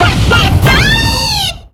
Cri de Goupelin dans Pokémon X et Y.